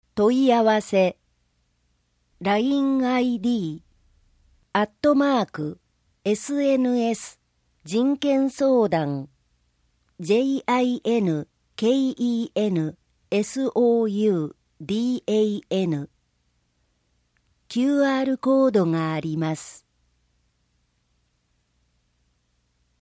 本号につきましては、平野区で活動されている「音訳ボランティアグループわたの実」の有志の皆様に作成いただきました。